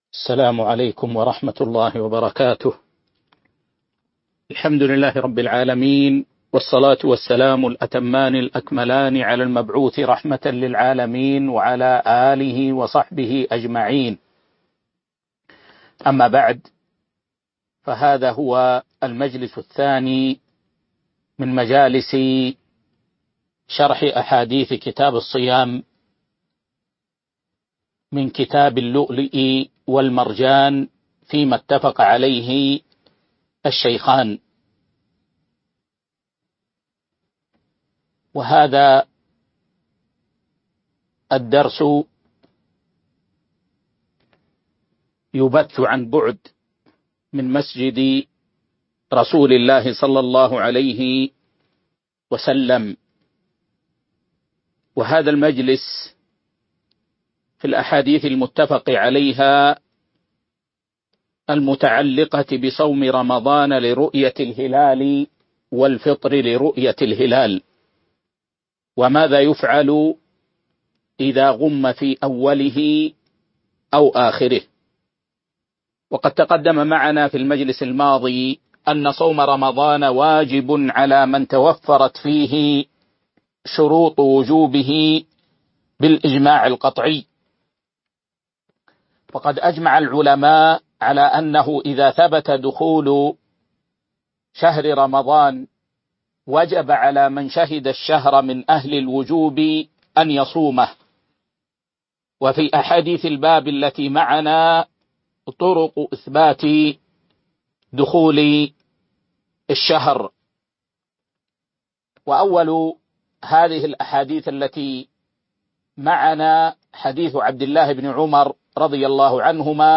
تاريخ النشر ٣ رمضان ١٤٤٢ هـ المكان: المسجد النبوي الشيخ